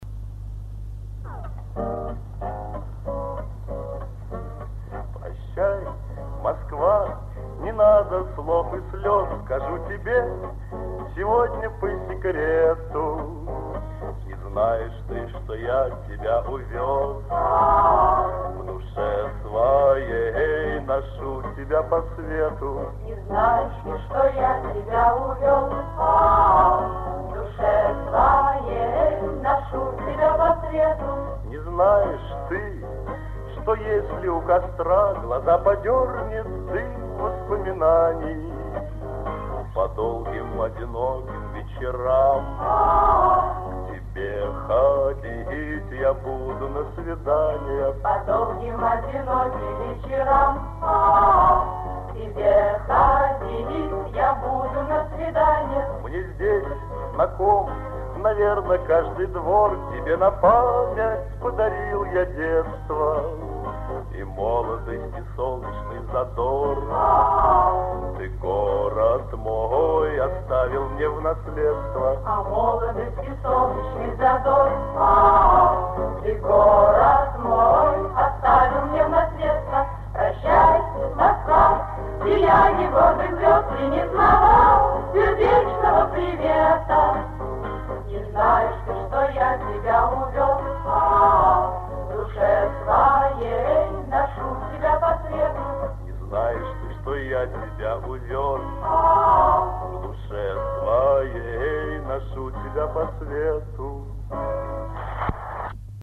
ФРАГМЕНТЫ КОНЦЕРТА-КОНКУРСА 27 АПРЕЛЯ 1959 Г. ДК МЭИ
6. Юрий Визбор  и женский ансамбль